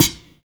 X SNARE 2.wav